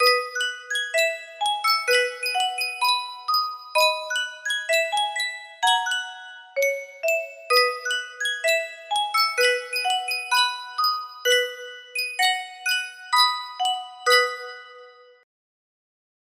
Sankyo Music Box - Nobody Knows the Trouble I've Seen GTX music box melody
Full range 60